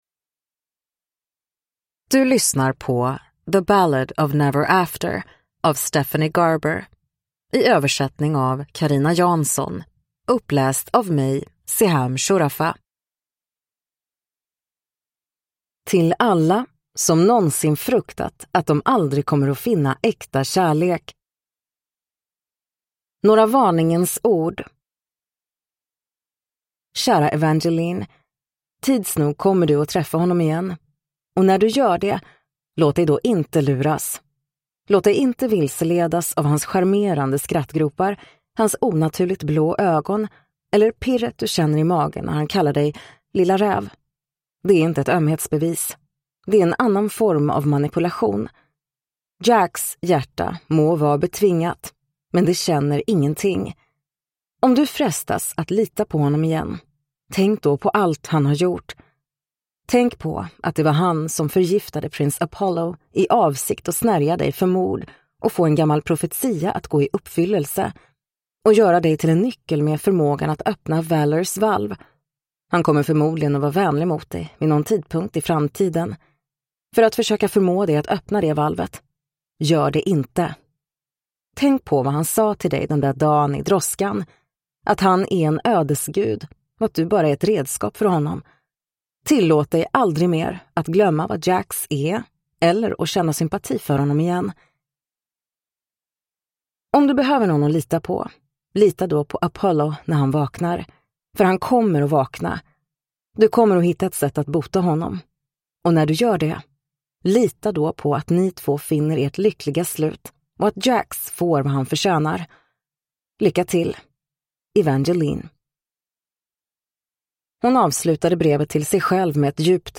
The Ballad of Never After (svensk utgåva) – Ljudbok